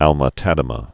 (ălmə-tădə-mə), Sir Lawrence 1836-1912.